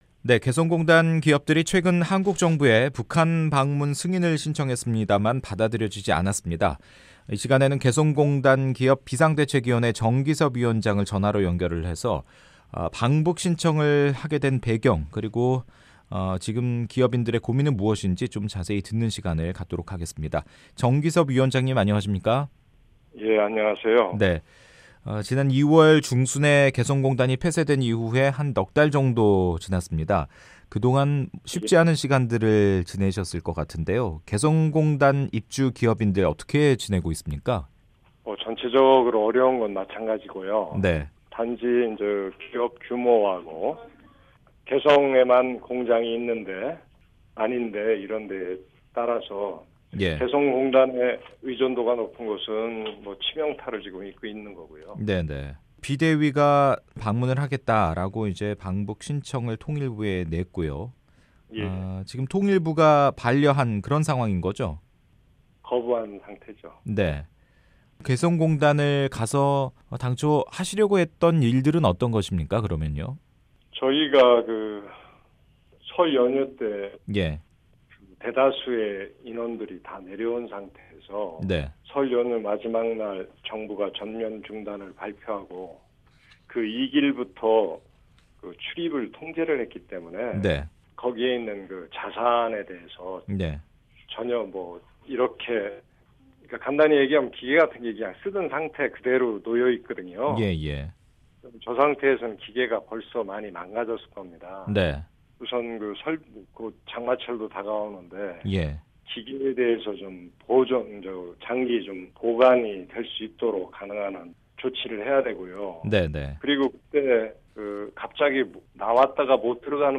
[인터뷰 오디오: